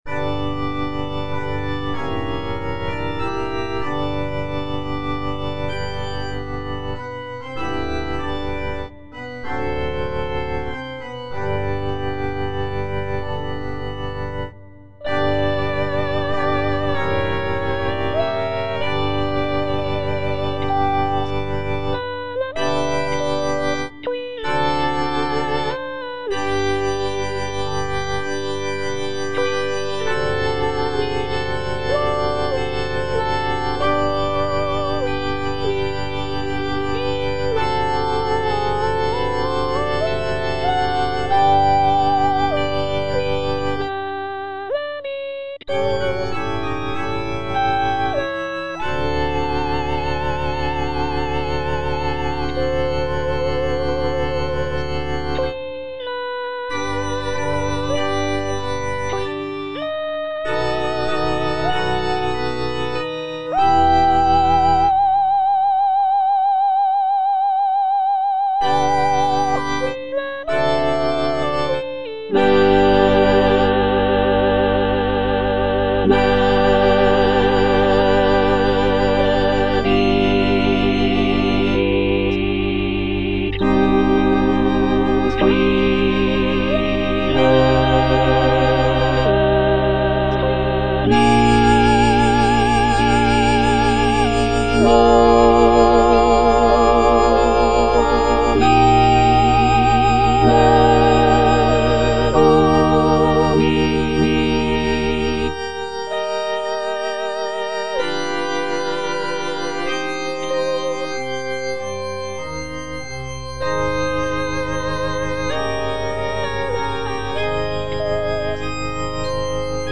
C.M. VON WEBER - MISSA SANCTA NO.1 Benedictus (All voices) Ads stop: auto-stop